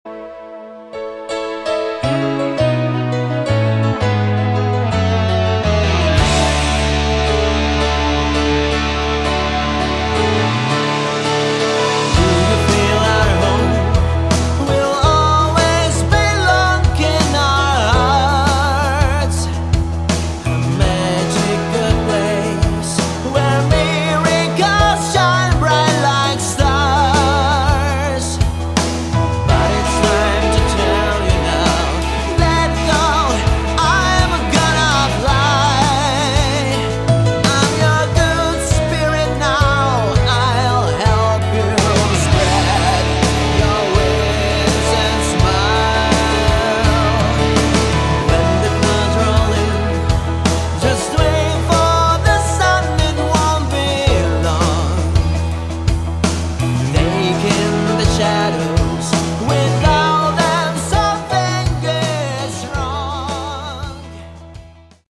Category: Melodic Rock / AOR
lead, backing vocals
guitars, keyboards
bass
drums & percussion